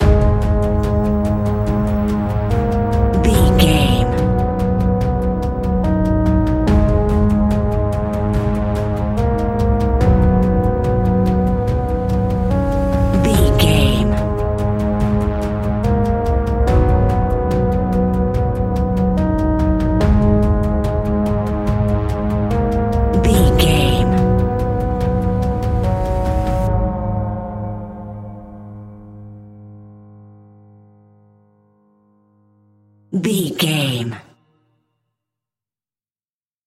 Aeolian/Minor
A♭
ominous
dark
haunting
eerie
piano
synthesizer
horror music
Horror Pads